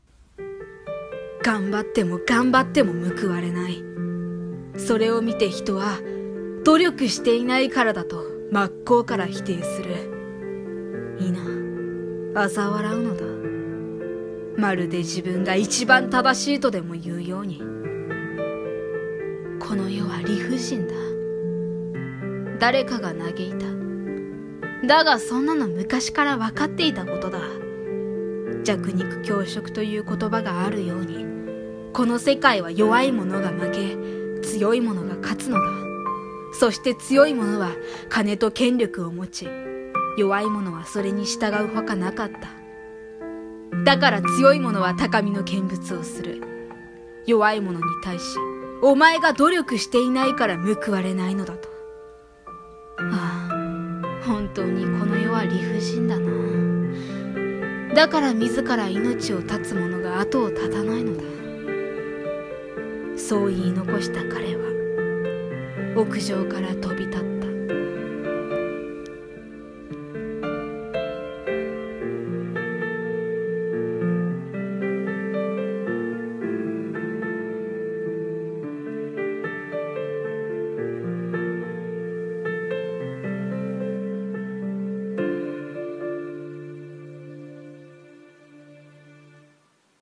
【一人声劇】